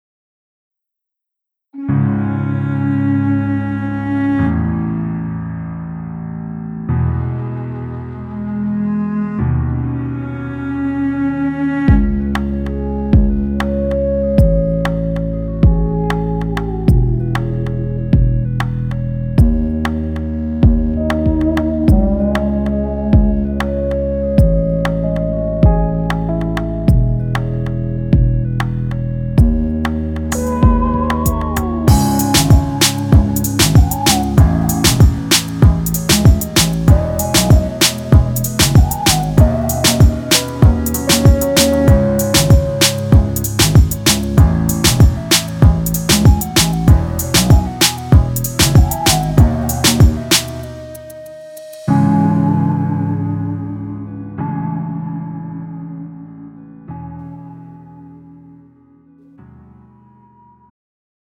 음정 남자키 장르 가요